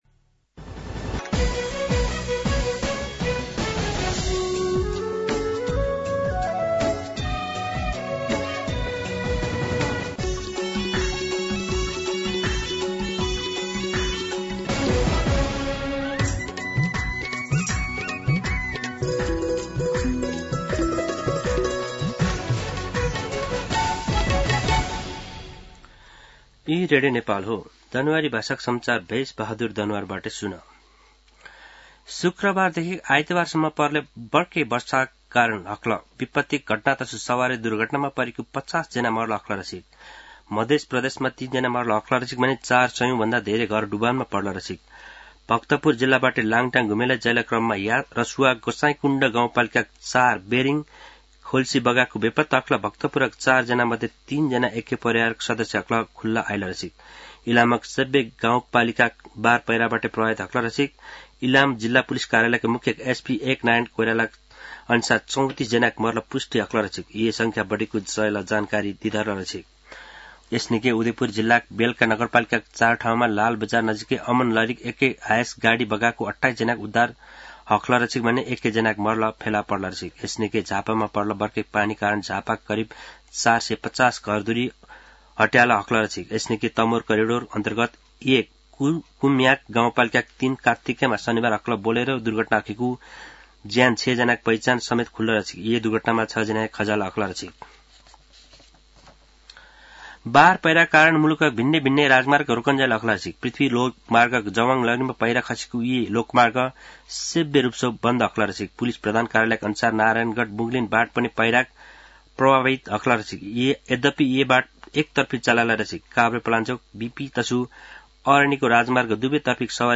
दनुवार भाषामा समाचार : १९ असोज , २०८२
Danuwar-News-1.mp3